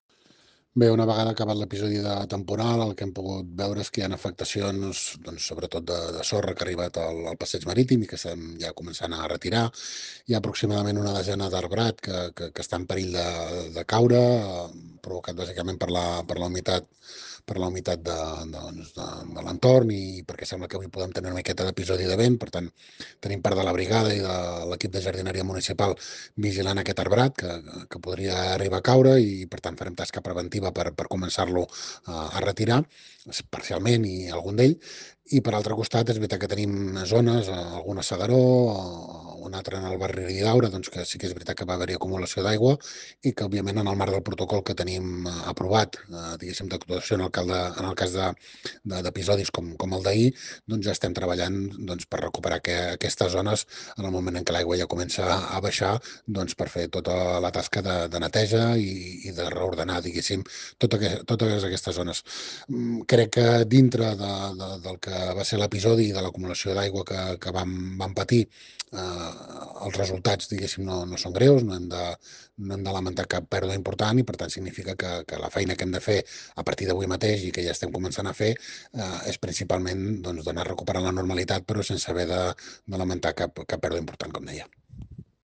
A Castell d’Aro, Platja d’Aro i s’Agaró, l’alcalde del municipi, Maurici Jiménez, també explica que la sorra de la platja també ha acabat arribant al passeig marítim.